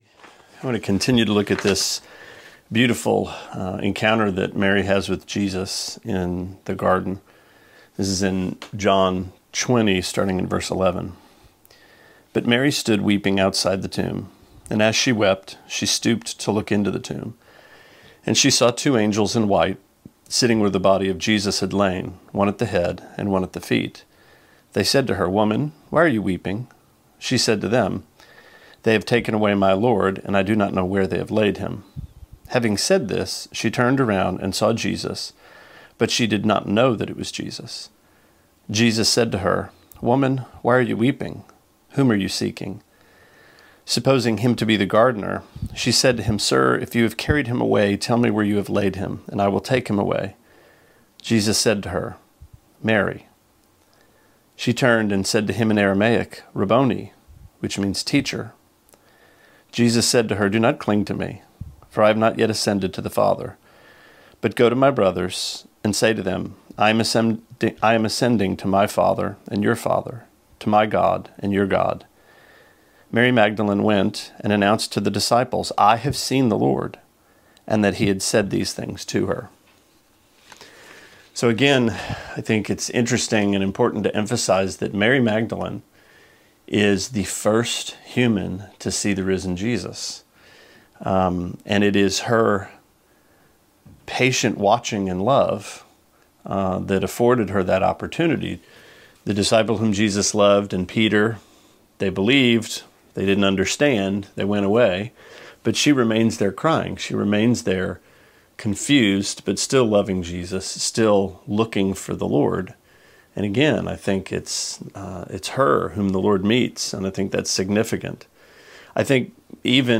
Sermonette 4/16: John 20:11-18: The Shepherd Calls His Sheep